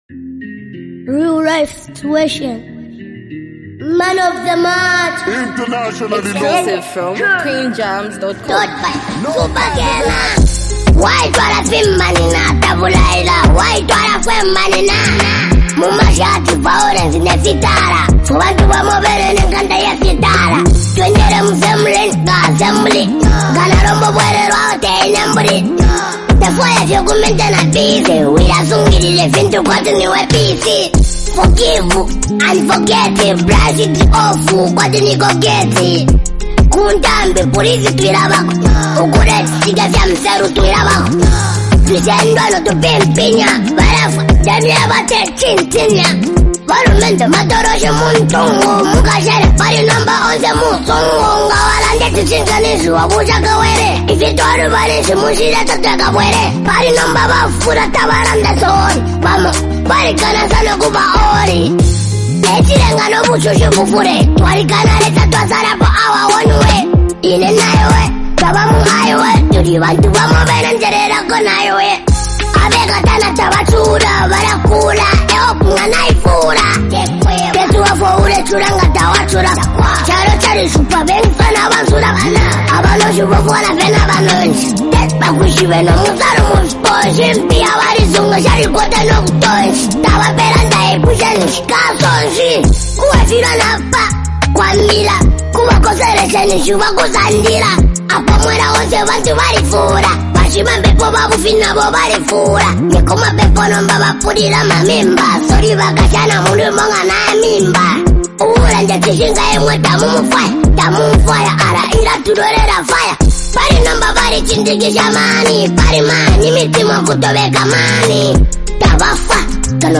bold and reflective track